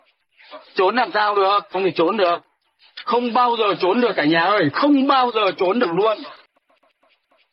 Trốn làm sao được meme sound effect
Thể loại: Câu nói Viral Việt Nam